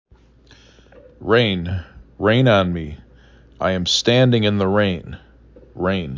rain 3 /r/ /A/ /n/ Frequency: 773
4 Letters, 1 Syllable
r A n